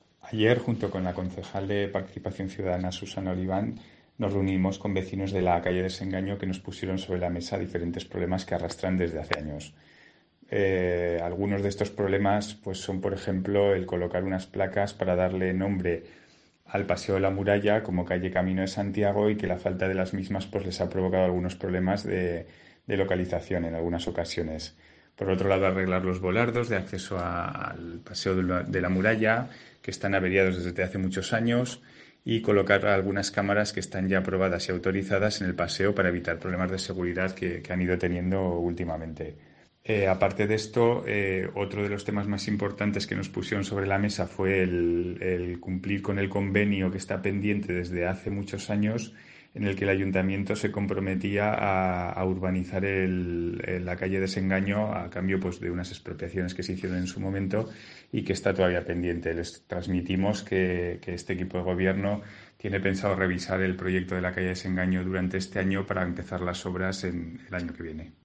El concejal de Urbanismo, Iván Rodriguez, habla de la calle Desengaño